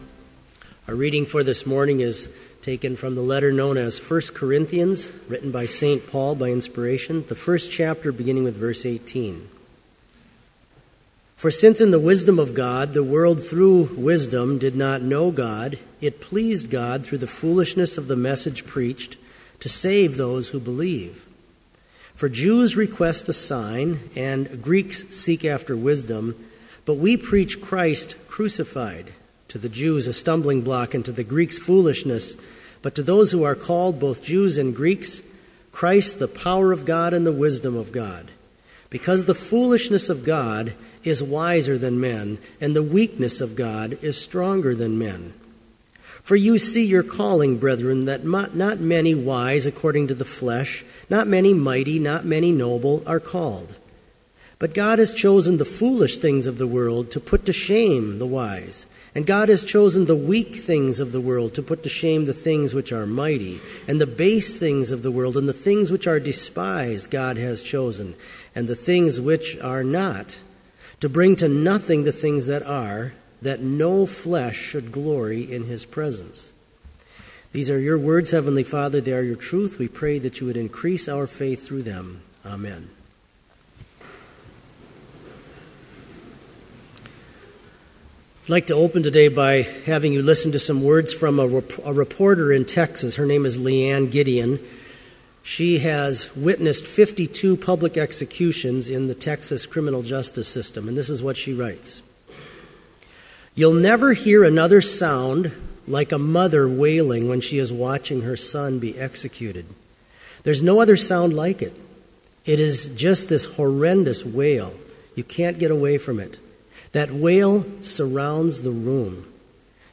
Complete Service
• Prelude
• Homily
• Postlude
This Chapel Service was held in Trinity Chapel at Bethany Lutheran College on Friday, February 14, 2014, at 10 a.m. Page and hymn numbers are from the Evangelical Lutheran Hymnary.